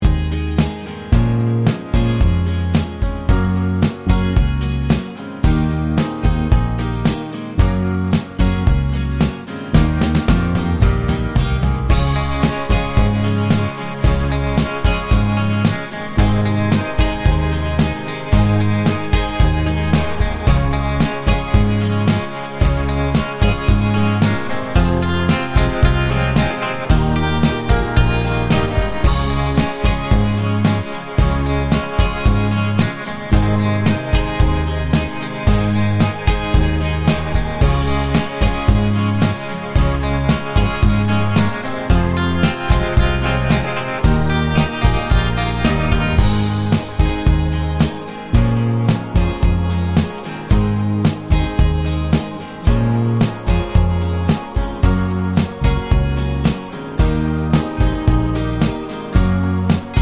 For Solo Singer Country (Male) 3:59 Buy £1.50